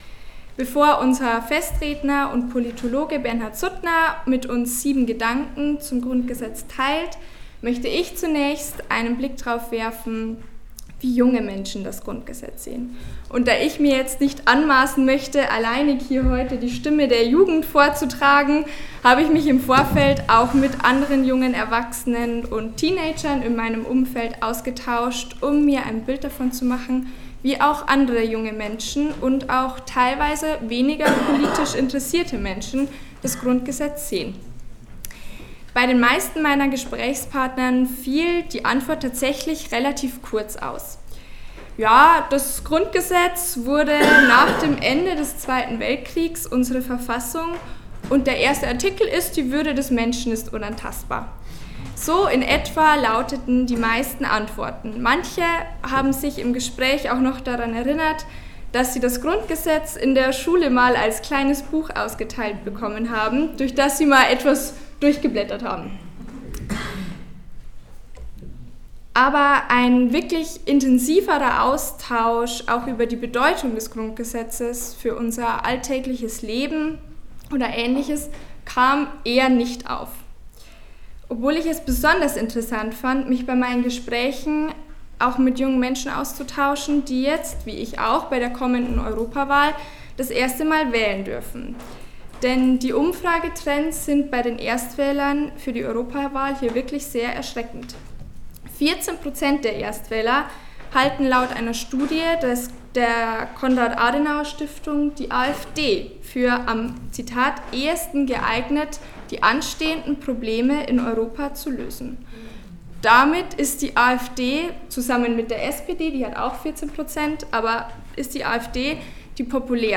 Hören Sie hier die Aufnahmen der beiden Reden:
Gut 100 Festgäste aus Kirchen, Politik, Schulen und Zivilgesellschaft sind der Einladung in den Festsaal St. Maximilian neben dem Passauer Dom gefolgt.